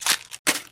Звук брошенной бумаги в мусорное ведро